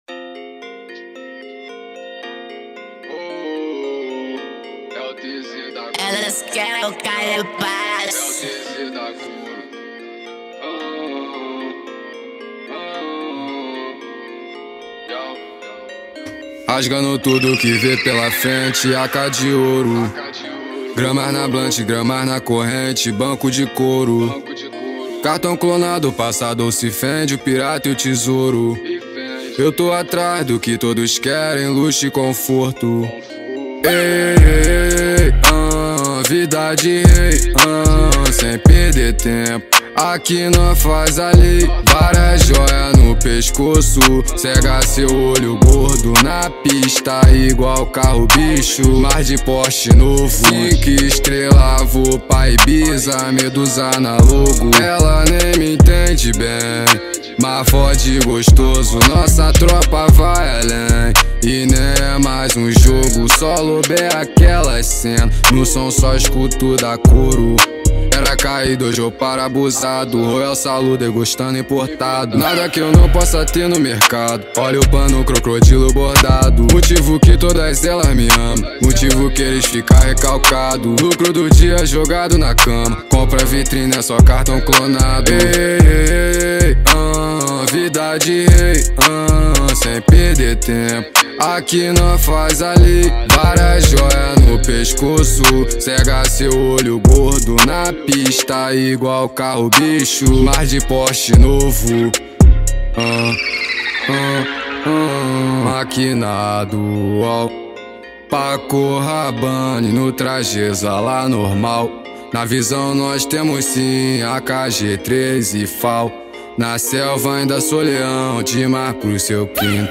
2024-07-23 14:34:24 Gênero: Trap Views